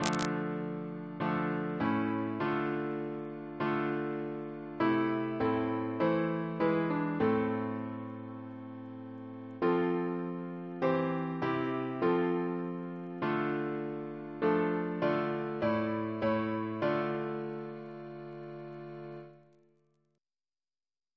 Double chant in D minor Composer: William Morley (1680-1731) Reference psalters: ACB: 136; ACP: 255; H1982: S195; OCB: 290; PP/SNCB: 180